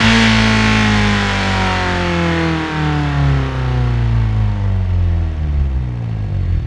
rr3-assets/files/.depot/audio/Vehicles/i4_02/i4_02_decel.wav
i4_02_decel.wav